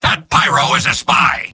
Robot-filtered lines from MvM. This is an audio clip from the game Team Fortress 2 .
{{AudioTF2}} Category:Soldier Robot audio responses You cannot overwrite this file.
Soldier_mvm_cloakedspyidentify04.mp3